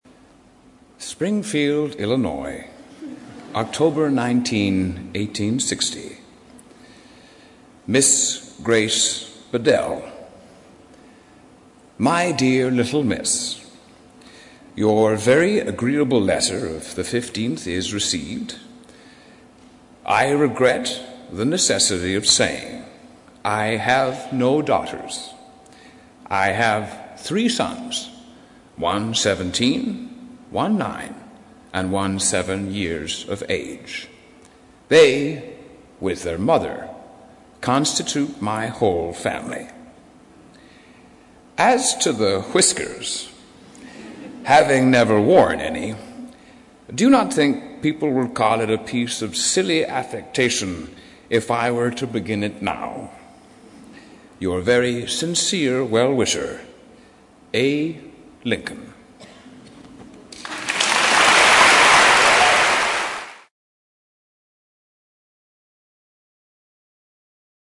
《见信如唔 Letters Live》是英国一档书信朗读节目，旨在向向书信艺术致敬，邀请音乐、影视、文艺界的名人，如卷福、抖森等，现场朗读近一个世纪以来令人难忘的书信。